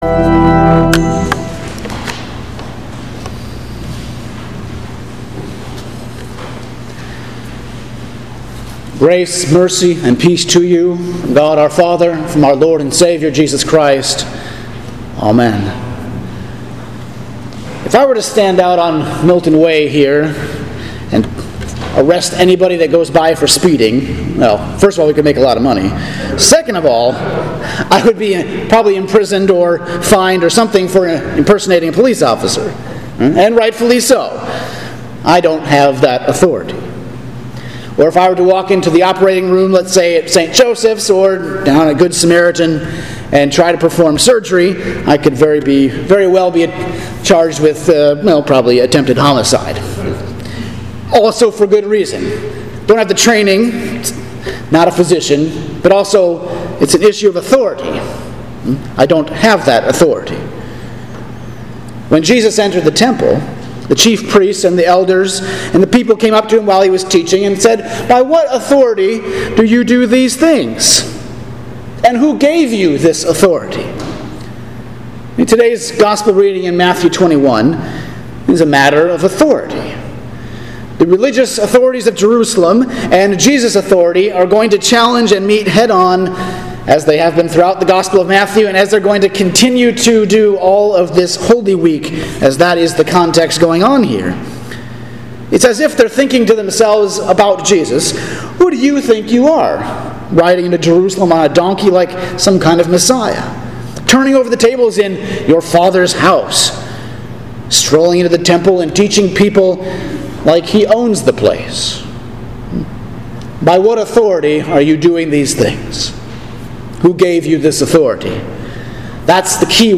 Sermon for Pentecost 17 – September 27, 2020